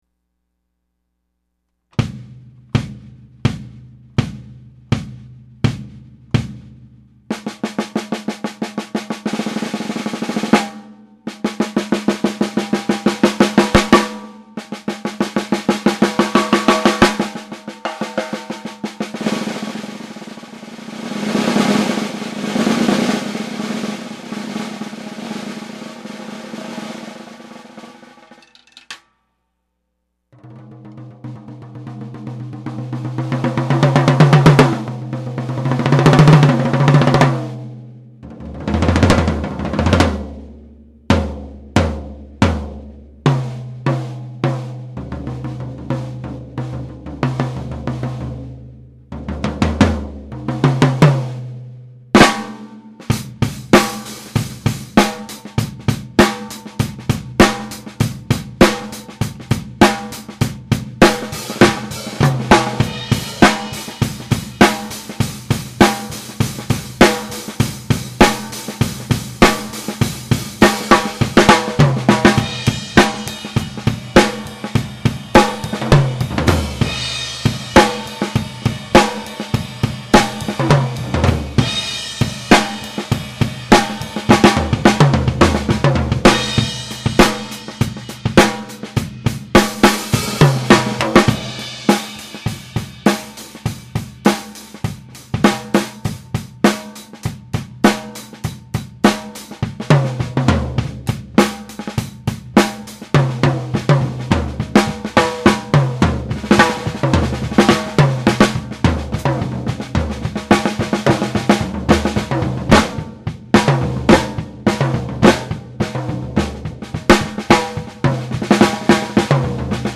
Jenkins-Martin fiberglass drum sound files - Page 1 - Vintage Drum Forum
"22 bass drum with Aquarian Super Kick 1 and the Evans pillow you had in the drum. Front head is a ported Ambassador
12 & 16 toms have clear Ambassador bottom, coated Ambassador top and zero muffling
Drums are recorded on an M-Audio Microtrack-II with a Tascam stereo mic just over the kit at head level. No EQ, compression, etc. and no other mics.
The room is small and padded down so there is little ambient coloring. It is not the most complimentary sounding drum room."
High tunings
High tuning.mp3